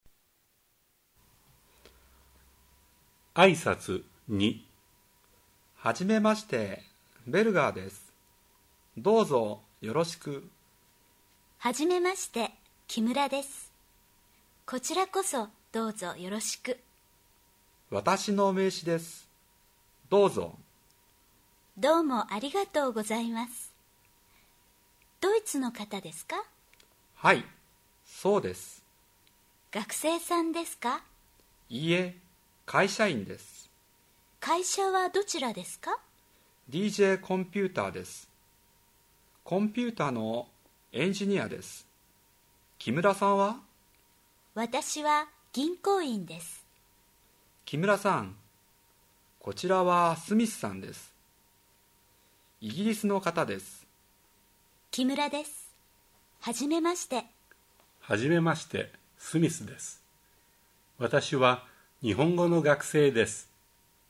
Texte, Dialoge und Übungen aus dem Lehrbuch "Grundkenntnisse Japanisch 1".